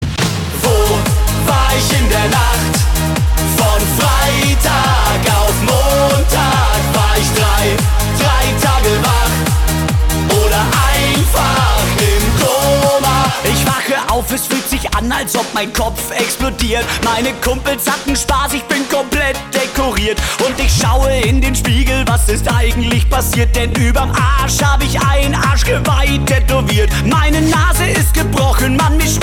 Gattung: Party-Hit
Besetzung: Blasorchester
Tonart: F-Dur